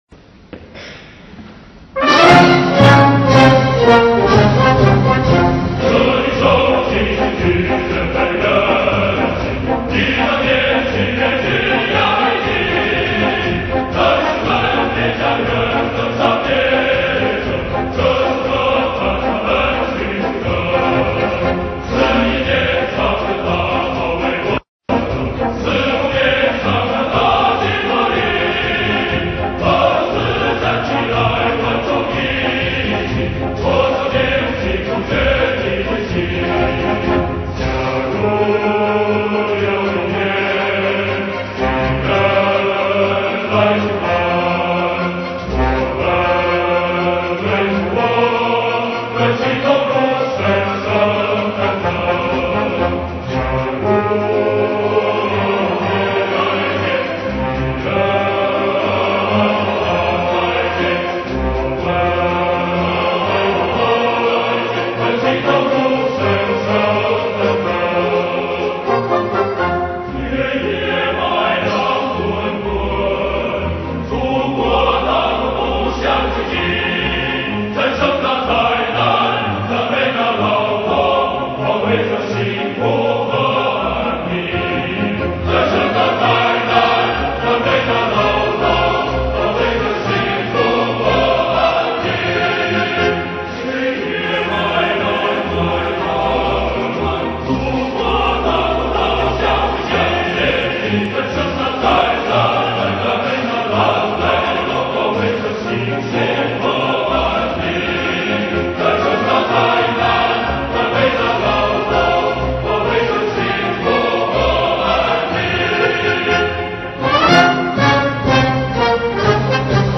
Марши
Описание: Китайский вариант легендарного марша!